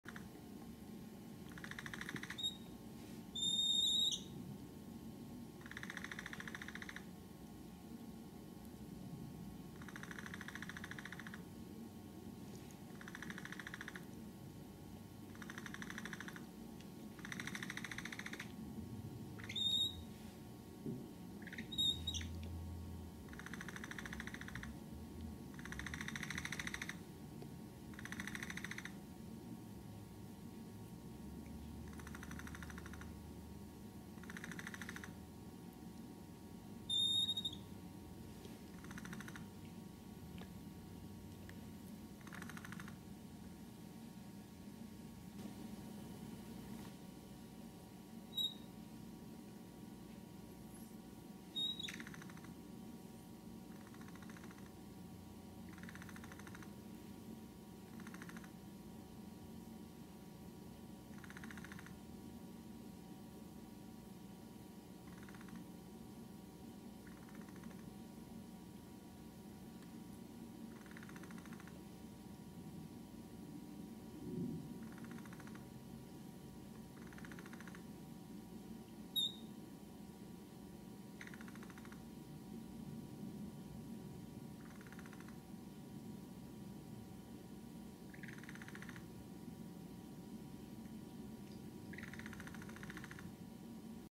На этой странице собраны натуральные записи: от умиротворяющего хруста травы до игривых возгласов в стае.
Звук пения маленькой капибары